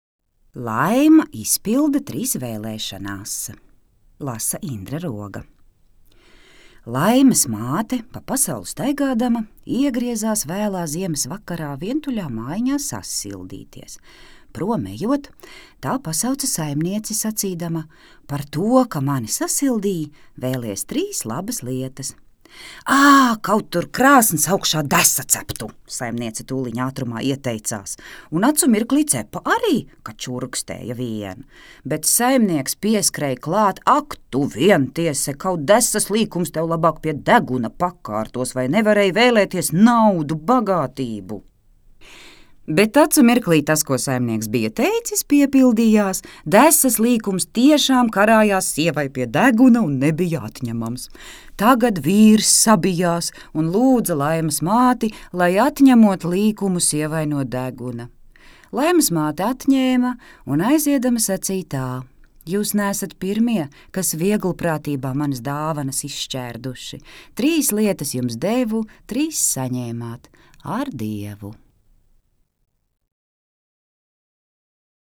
Teicējs: Indra Roga